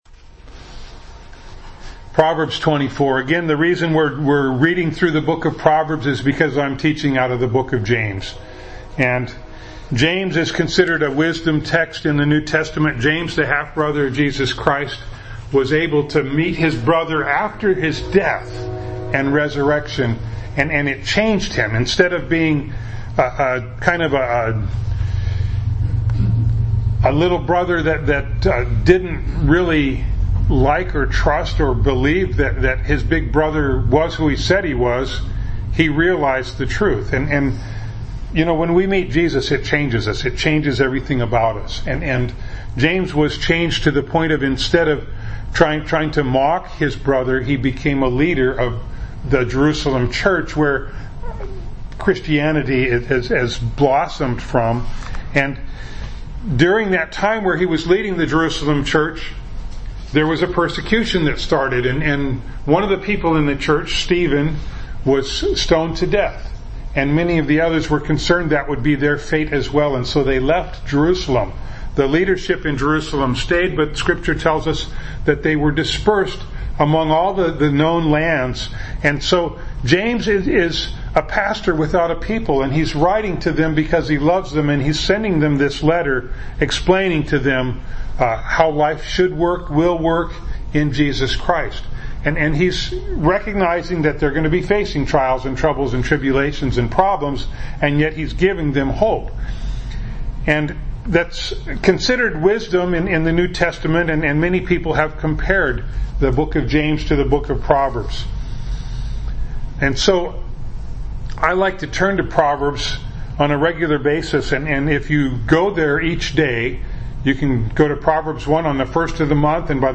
Bible Text: James 1:23-25 | Preacher